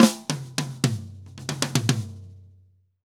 Drum_Break 110_1.wav